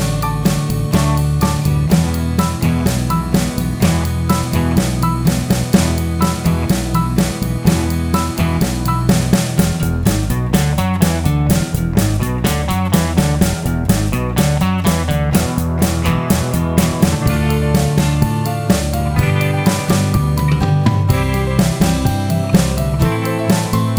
no Backing Vocals Pop (1960s) 2:58 Buy £1.50